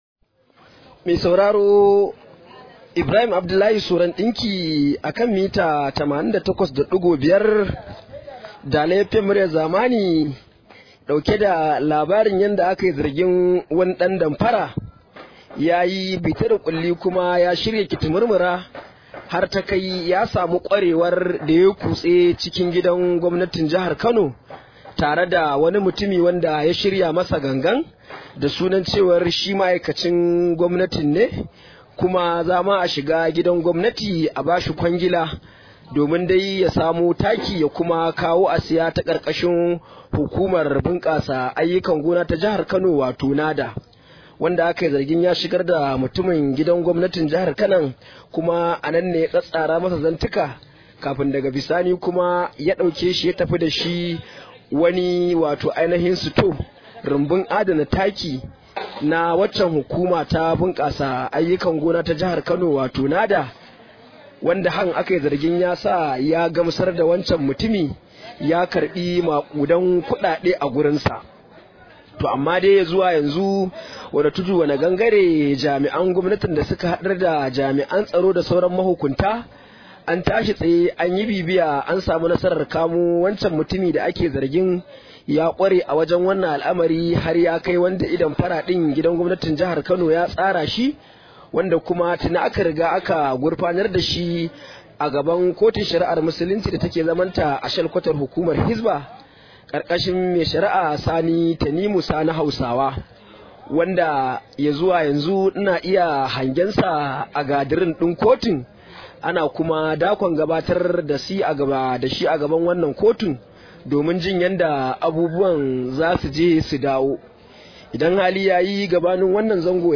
Rahoto: Ana zargin wani ɗan damfara da yin kutse cikin gidan gwamnatin Kano